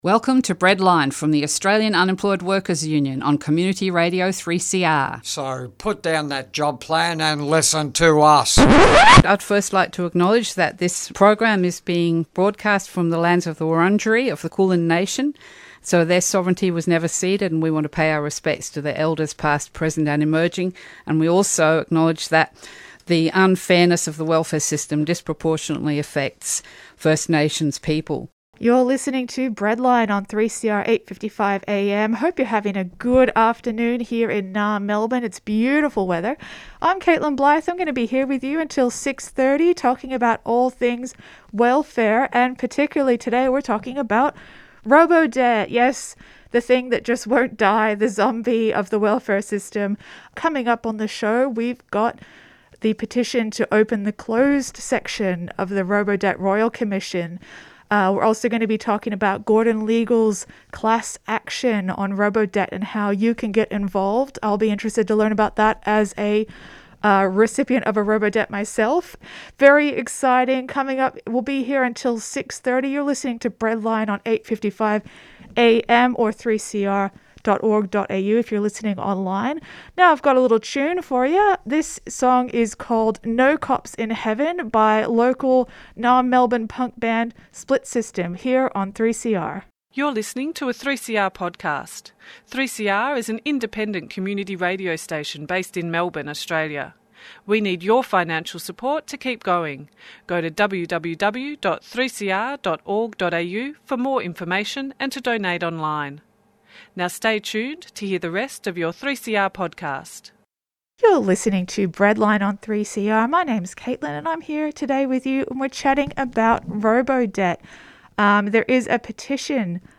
Tweet Breadline Monday 6:00pm to 6:30pm By the unemployed for the unemployed. Australian Unemployed Workers Union members will cover the latest inequities of the "welfare" system and how we are organising to fight back!